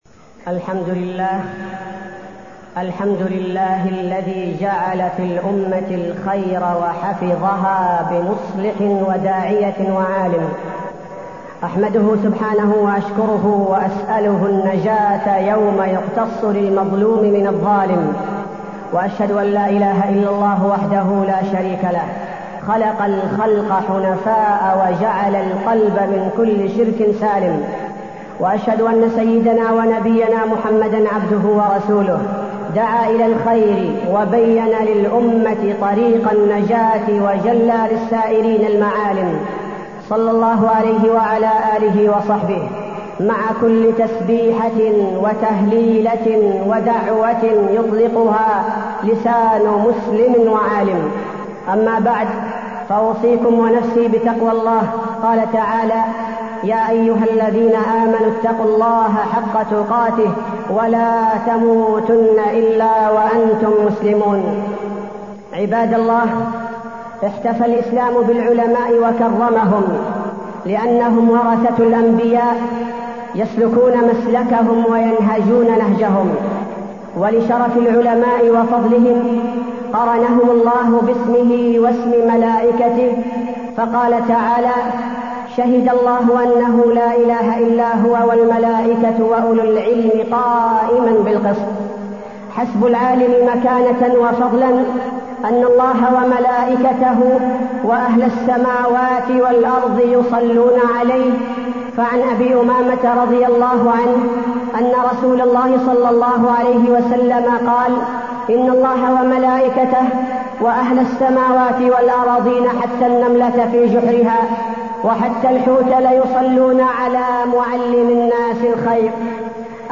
تاريخ النشر ٢٤ شوال ١٤٢١ هـ المكان: المسجد النبوي الشيخ: فضيلة الشيخ عبدالباري الثبيتي فضيلة الشيخ عبدالباري الثبيتي مكانة العلماء